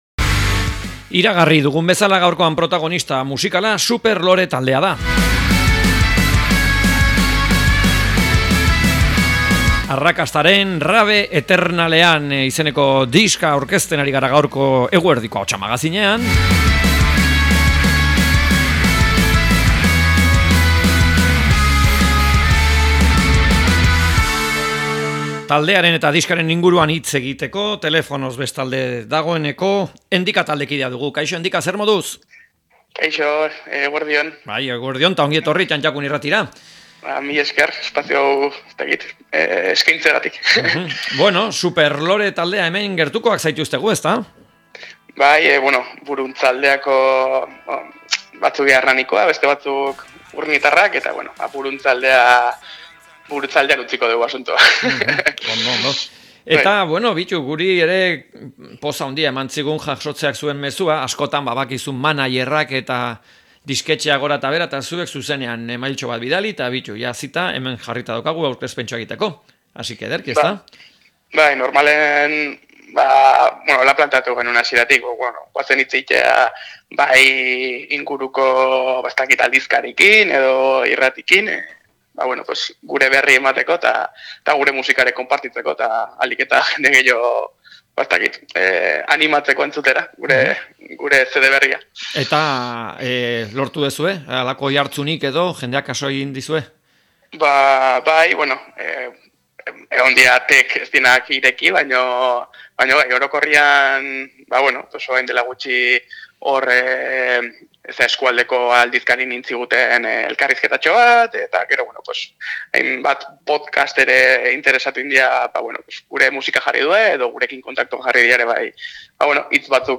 Superlore taldeari elkarrizketa